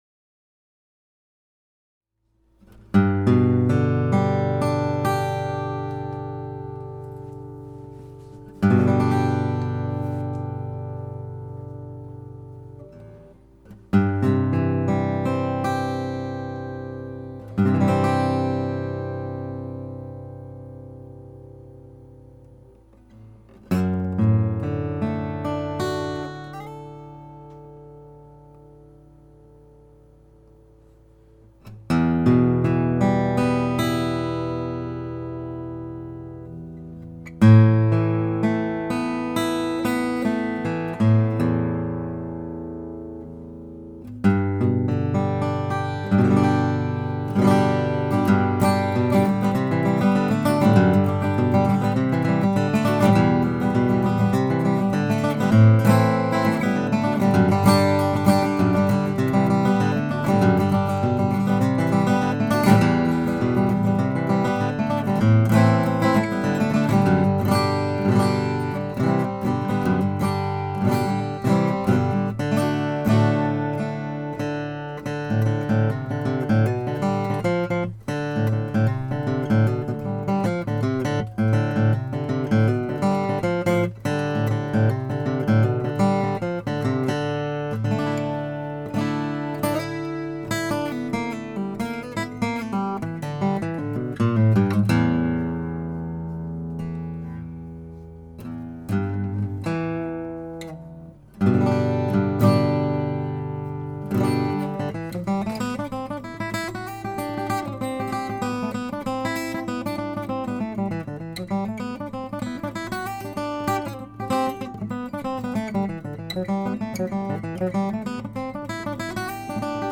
Many of these were made here in the shop about as simply as they could be done.
00-M No166, small body, short scale, still sounds full and strong.